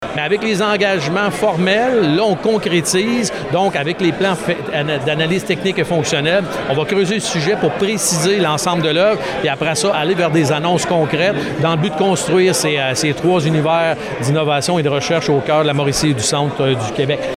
Lors de cette conférence de presse, la VTE a dévoilé les grandes lignes de son Plan stratégique 2024-2028.